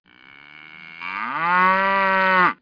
1 channel
cowmoo08.mp3